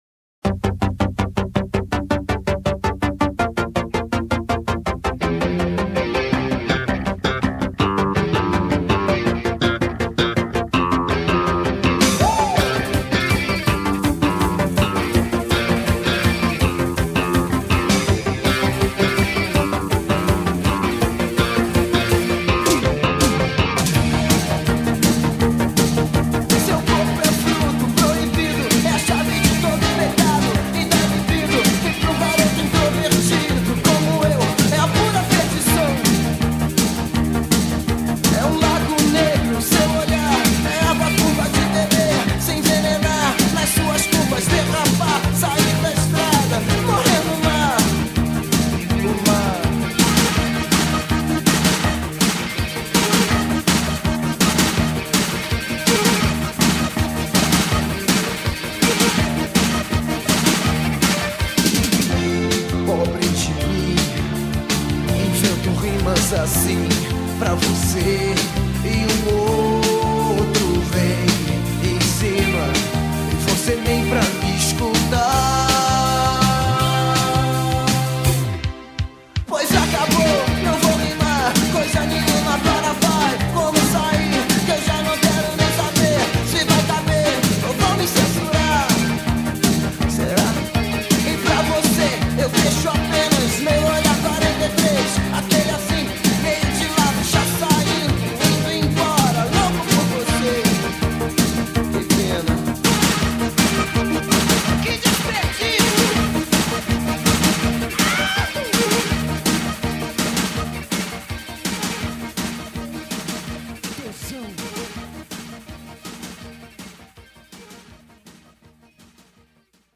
BPM164
Audio QualityMusic Cut